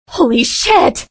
Tag: 讲话 谈话 声音 女孩 性感 英语 女性 女人 美国 声乐 诅咒